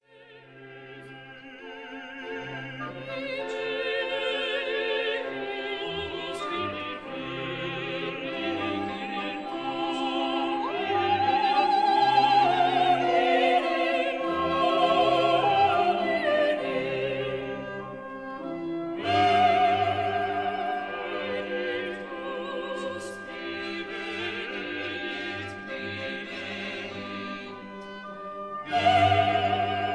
Recorded in the Musikverein, Vienna in October 1956 (Stereo)